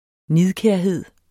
Udtale [ ˈniðkεɐ̯ˌheðˀ ]